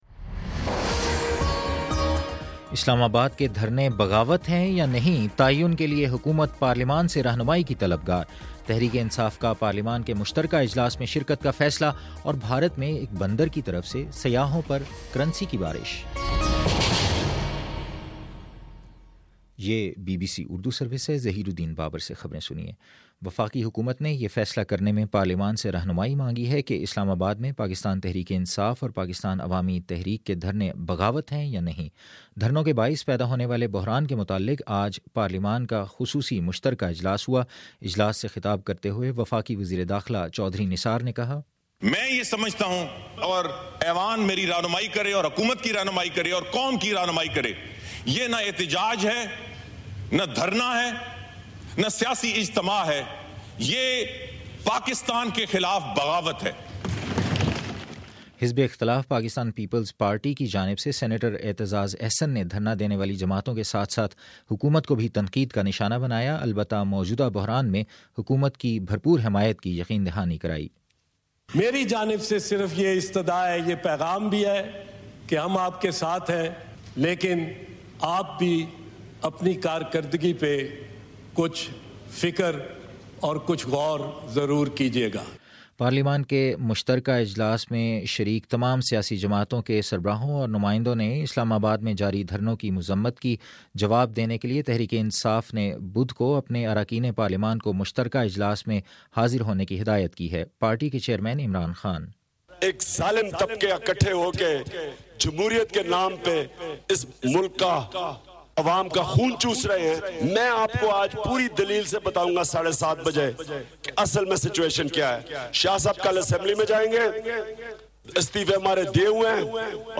دو ستمبر : شام سات بجے کا نیوز بُلیٹن
دس منٹ کا نیوز بُلیٹن روزانہ پاکستانی وقت کے مطابق صبح 9 بجے، شام 6 بجے اور پھر 7 بجے۔